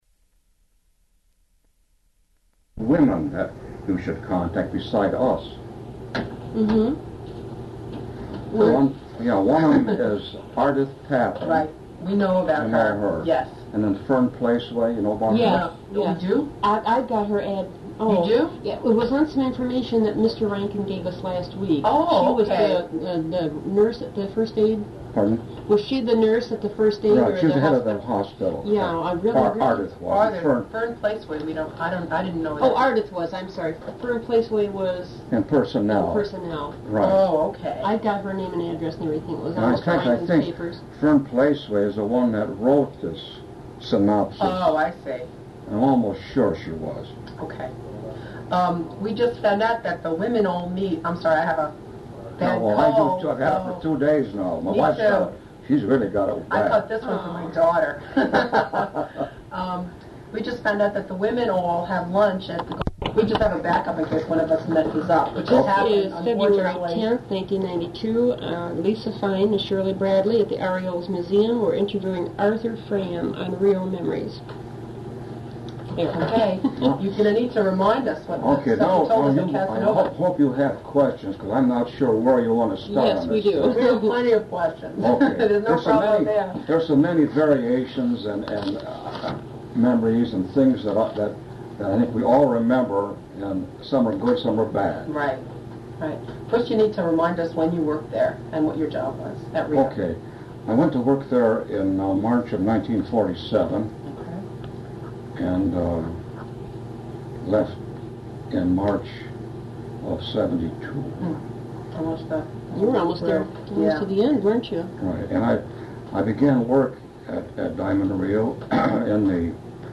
Recorded as part of the REO Memories oral history project.